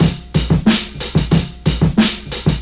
• 122 Bpm HQ Breakbeat A Key.wav
Free drum groove - kick tuned to the A note. Loudest frequency: 1353Hz
122-bpm-hq-breakbeat-a-key-HLj.wav